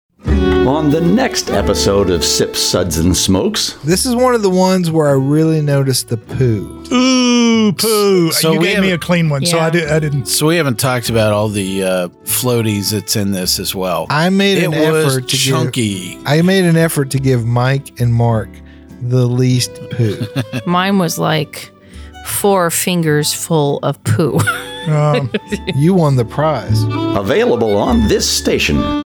192kbps Mono